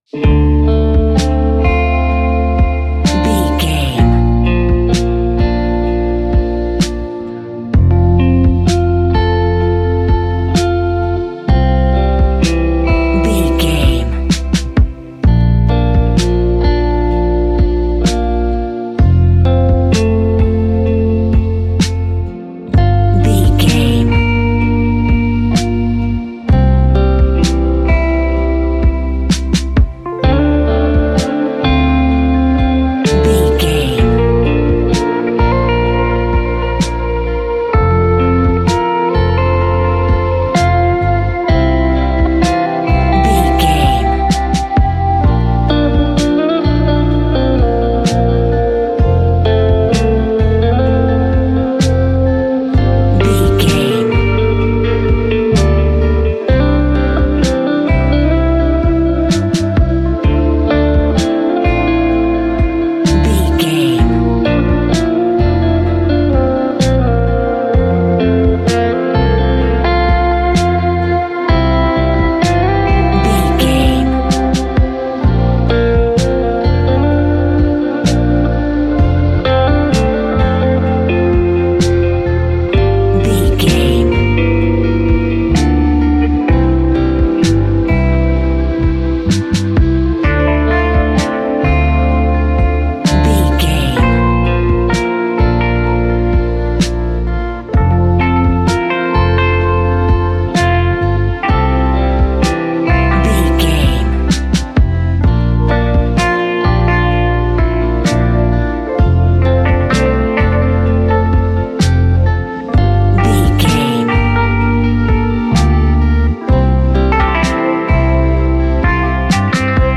Ionian/Major
chilled
laid back
sparse
chilled electronica
ambient
atmospheric
instrumentals